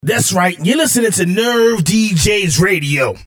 Acapellas